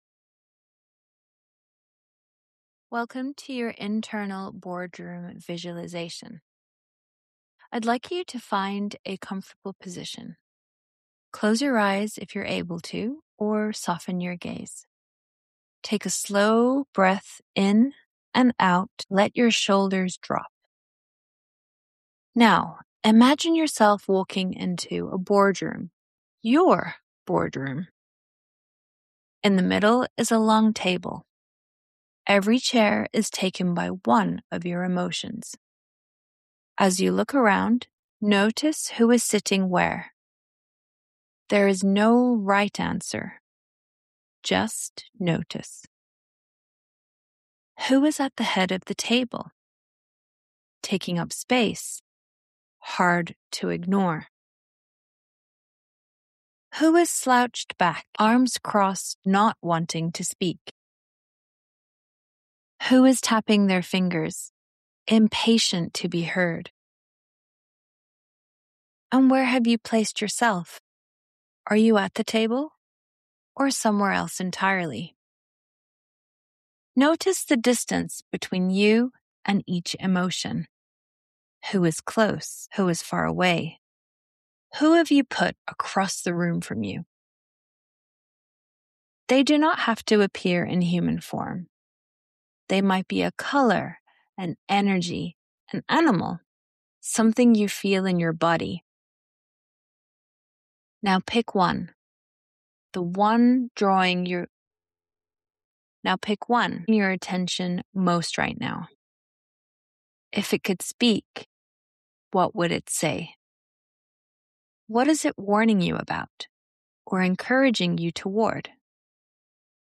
I have recorded a short visualisation to take you through this exercise.